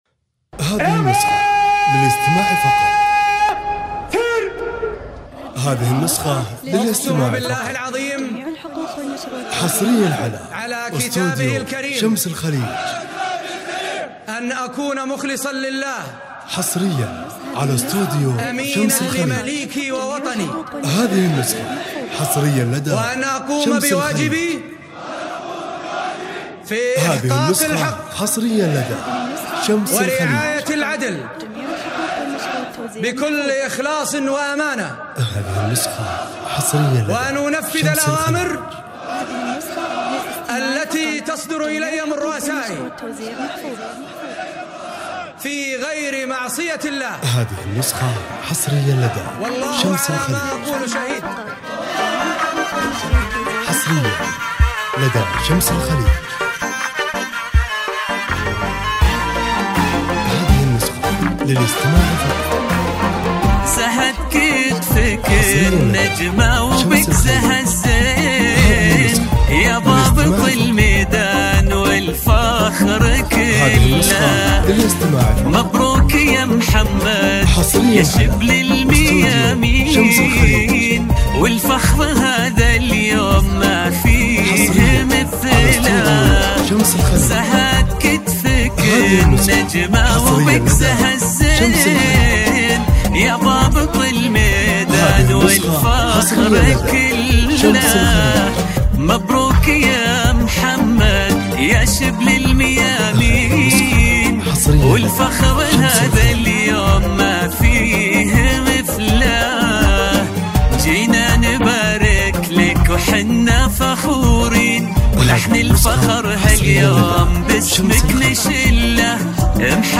زفة موسيقية فاخرة تُخلّد لحظة المجد والانتصار.
زفة تخرج عسكرية موسيقية فخمة